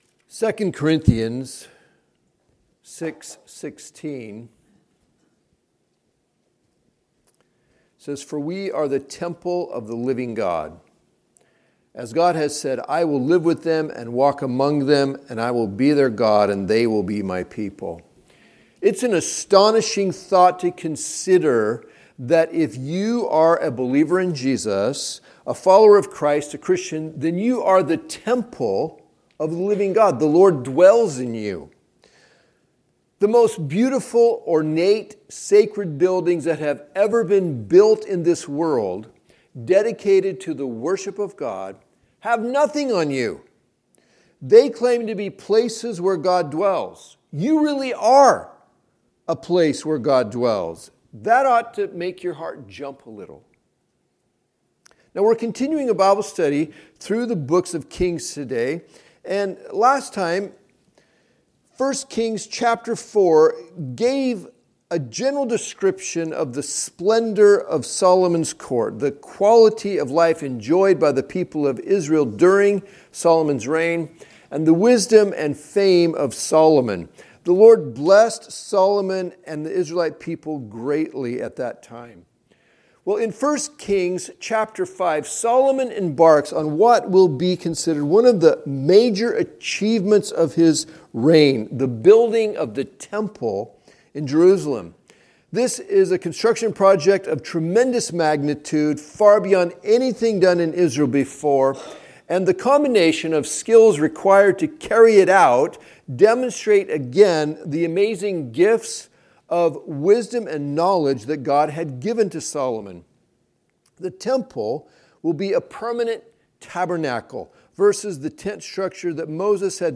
Sunday Morning Teachings